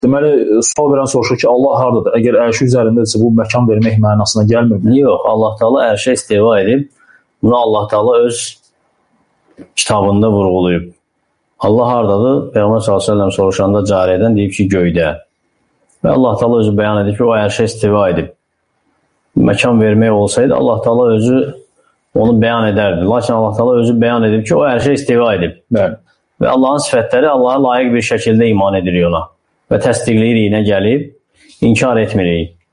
Sual-cavab